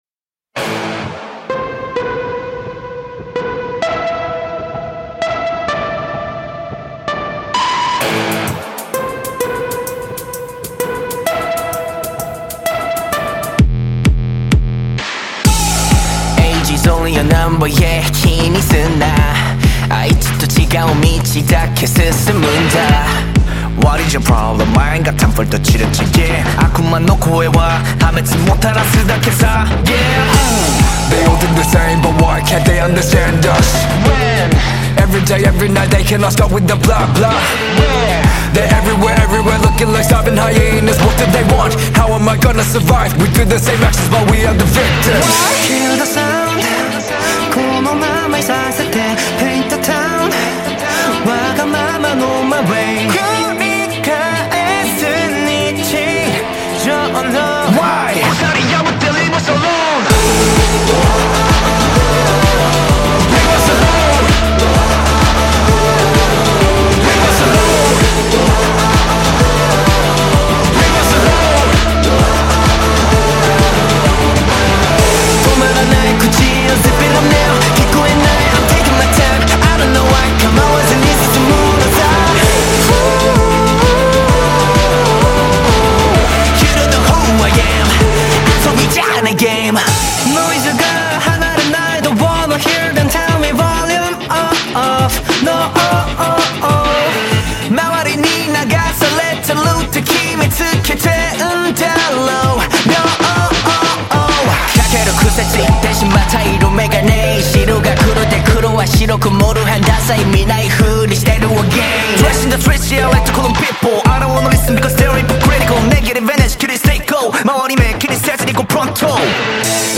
KPop
Label Dance